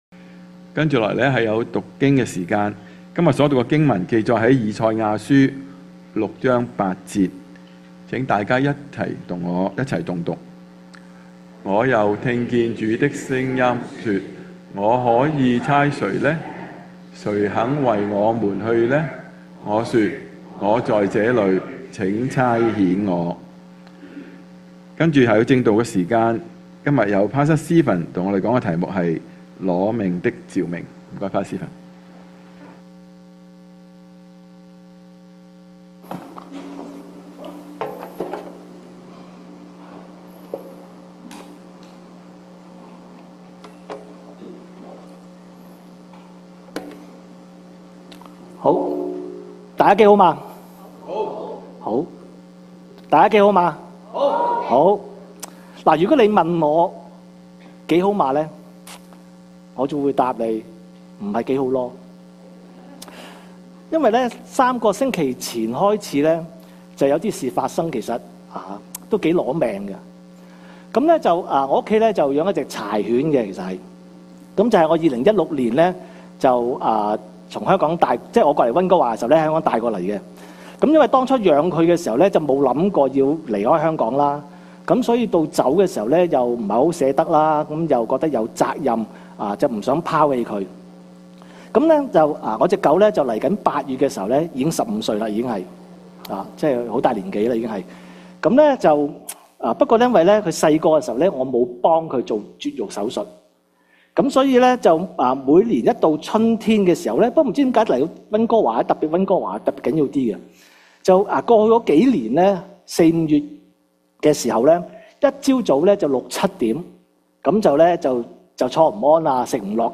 講道錄像 信息:《攞命的召命》 經文
粵語堂主日崇拜-《攞命的召命》-《以賽亞書-6-8節-》.mp3